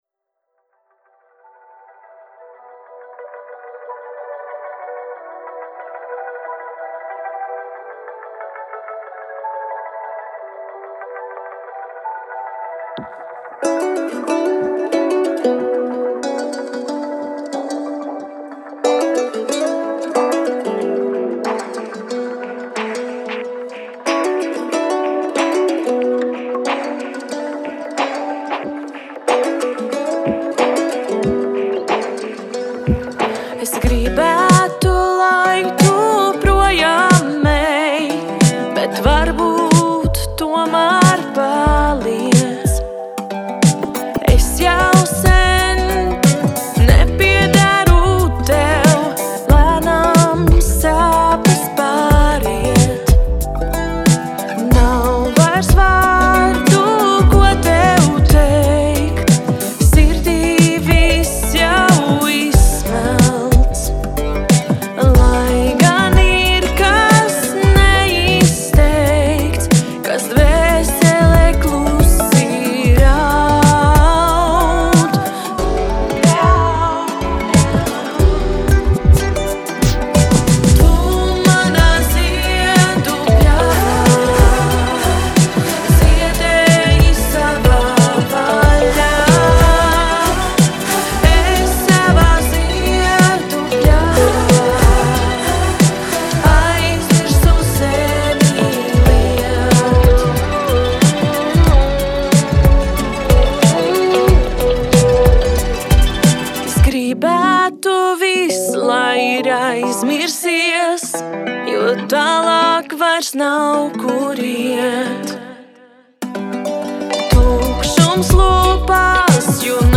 Поп песнь
Аранж, запись, сведенийо Жанр поп. язык латышский, голос женский, кое где мой) Свежак, закончил песню, ухи слиплись)) Тяжёлая работа...